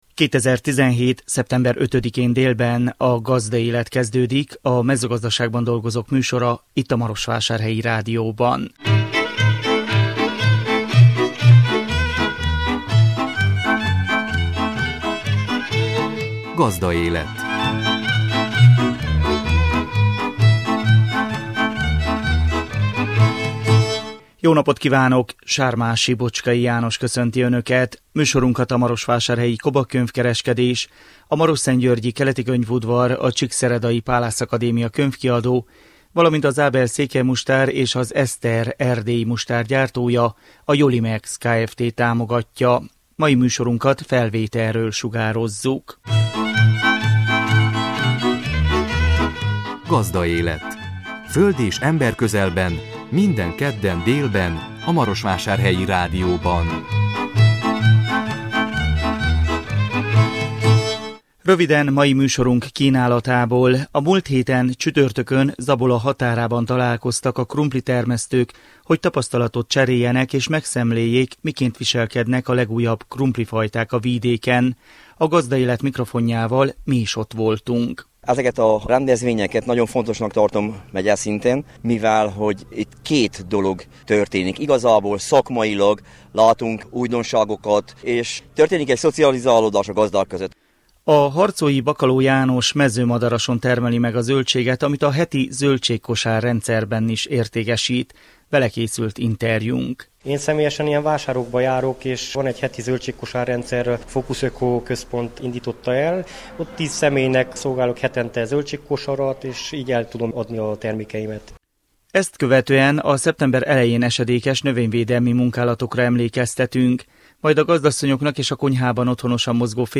A 2017 szeptember 5-én jelentkező műsor tartalma: A múlt héten, csütörtökön Zabola határában találkoztak a krumpli termesztők, hogy tapasztalatot cseréljenek és megszemléljék, miként viselkednek a legújabb krumplifajták a vidéken. A Gazdaélet mikrofonjával mi is ott voltunk.
Vele készült interjúnk.